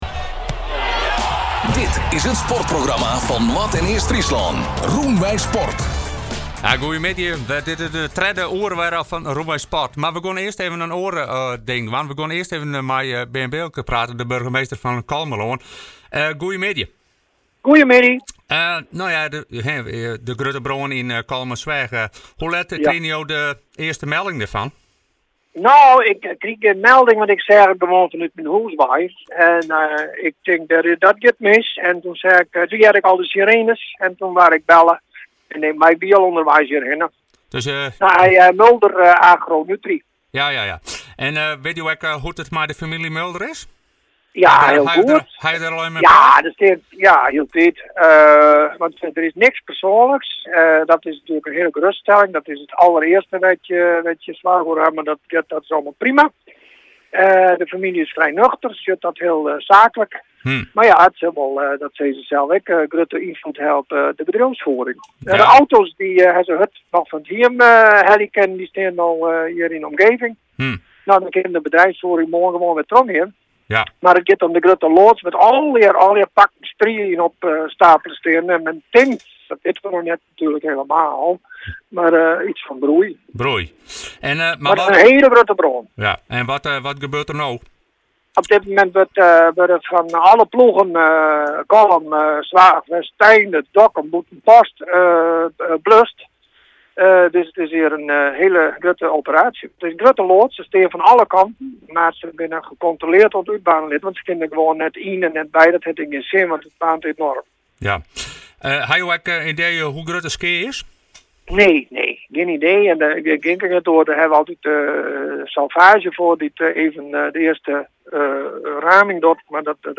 Luister hieronder naar burgemeester Bearn Bilker van Kollumerland.
Interview-Bearn-Bilker.mp3